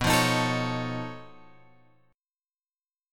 B 9th Flat 5th